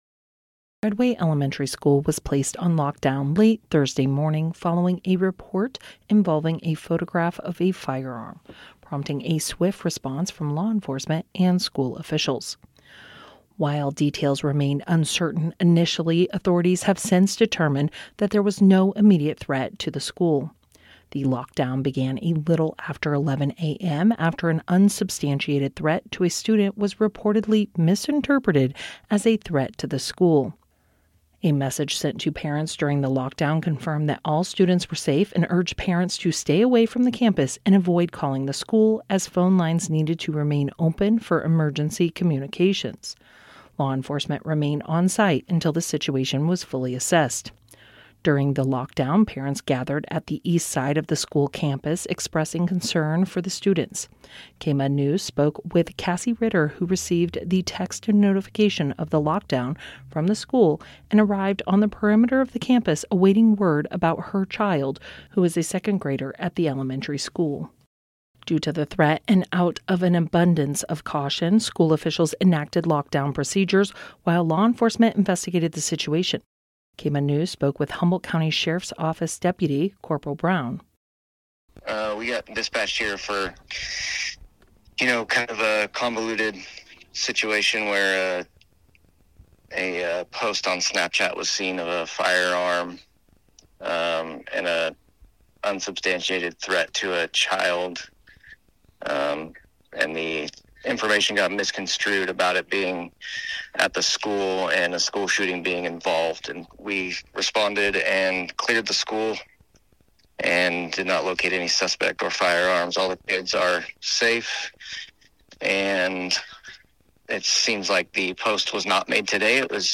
KMUD News